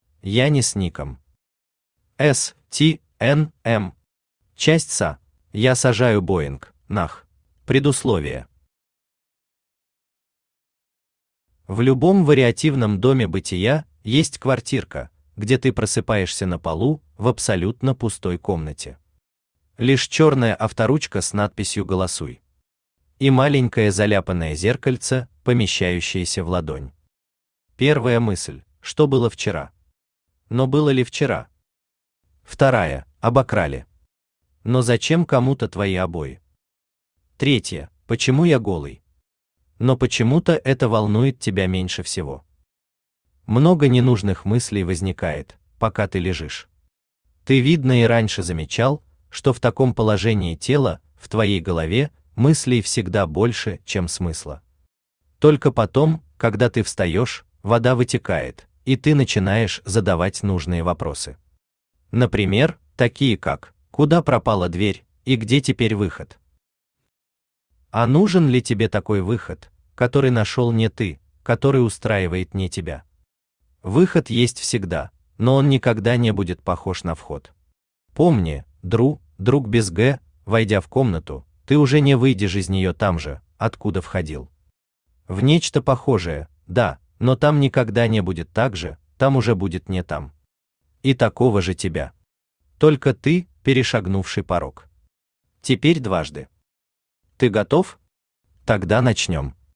Аудиокнига S.T.N.M. часть «Sa» | Библиотека аудиокниг
Aудиокнига S.T.N.M. часть «Sa» Автор Янис Ником Читает аудиокнигу Авточтец ЛитРес.